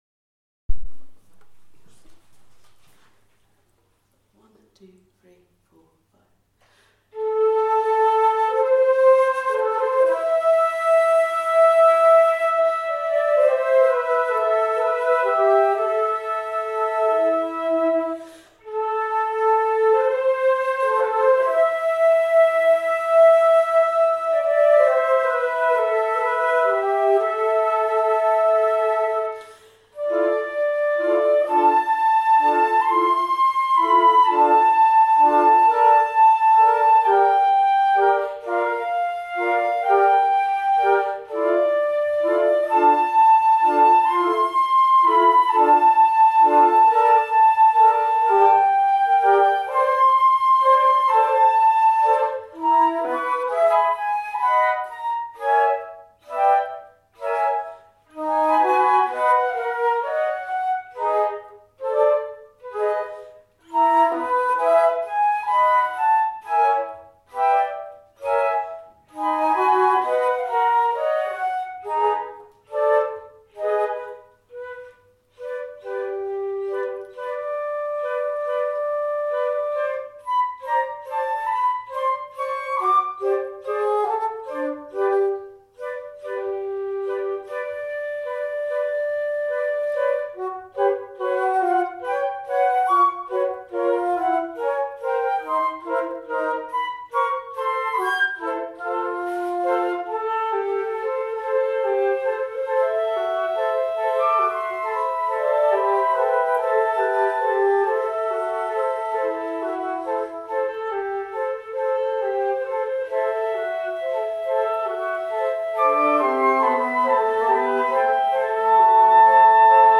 let there be flute take 1